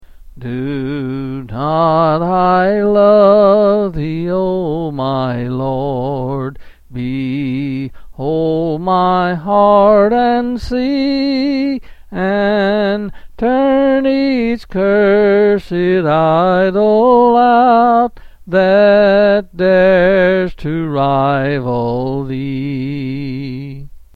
Quill Selected Hymn
C. M.